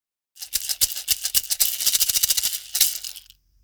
ひょうたんマラカス
マダガスカル産のひょうたんと竹でできたマラカスです。中に小石や種が入っていて、シャカシャカとご機嫌なサウンドがします。もち手がしっかりして優しい音がするため、高齢者や福祉施設でも使われています。
素材： ひょうたん 竹 小石